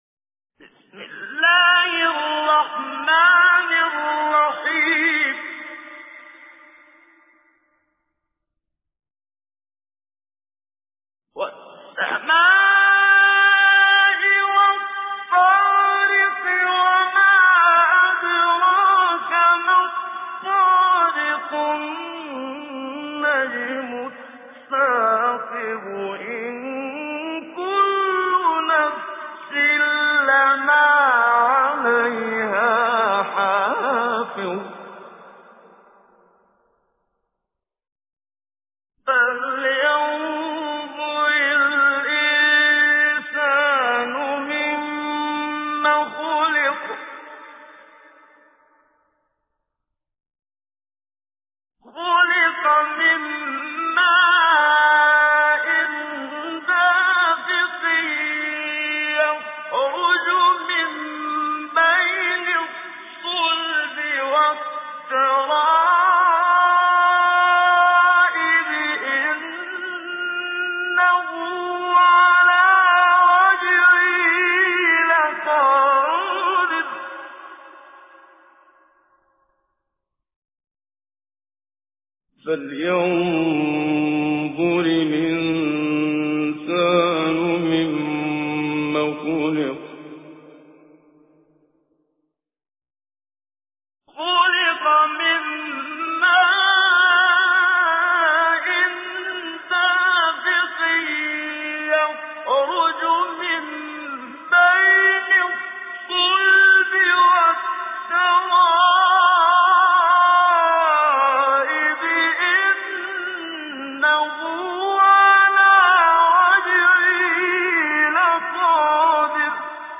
تجويد
سورة الطارق الخطیب: المقريء الشيخ محمد صديق المنشاوي المدة الزمنية: 00:00:00